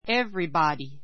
everybody 中 A1 évribɑdi エ ヴ リバディ ｜ évribɔdi エ ヴ リボディ 代名詞 誰 だれ でも , みんな （everyone） ⦣ 単数扱 あつか い. 意味・用法ともeveryoneと同じ.